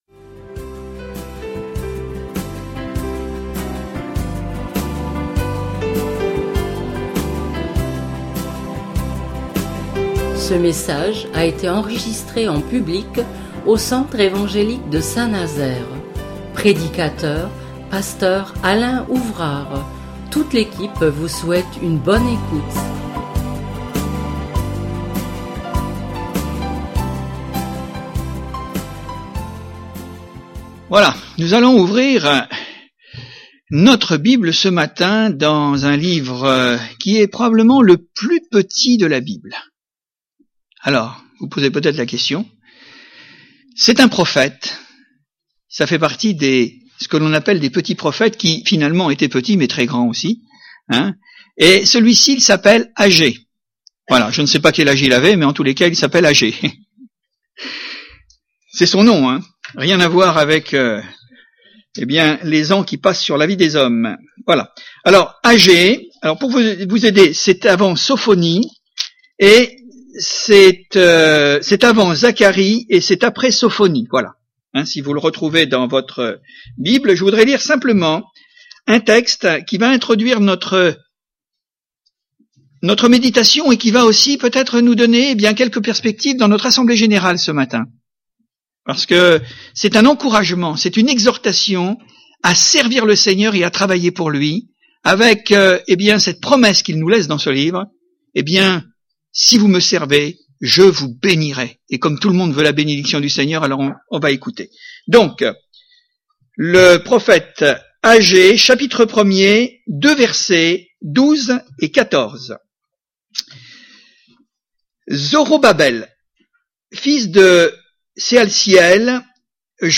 Exhortation à servir Dieu. Il existe deux sortes de zèle ; le premier est animé par notre volonté qui durera autant que nos motivations charnelles y trouveront des intérêts et le second, inspiré et soutenu par l’Esprit de Dieu lequel est capable de transcender nos faiblesses et qui font dire… avec Dieu nous ferons des exploits !